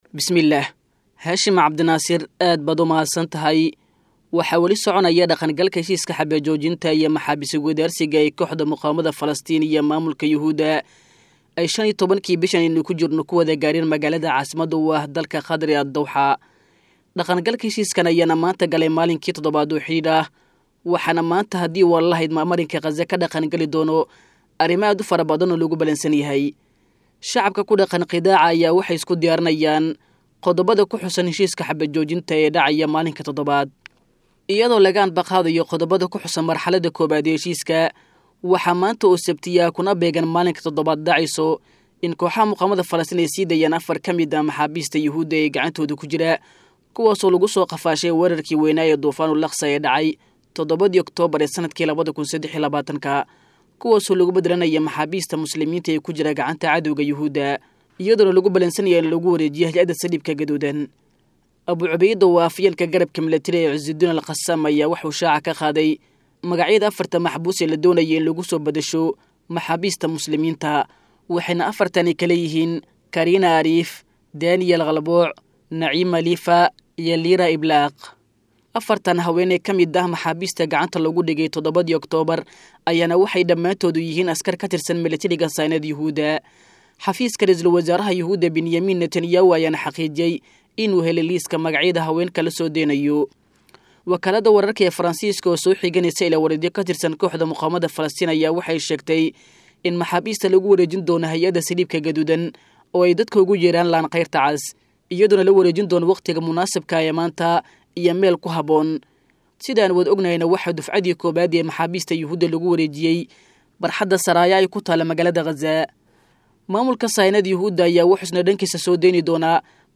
Xabad Joojinta Magaalada Qaza oo Gashay Maalinkii Todobaad.[WARBIXIN]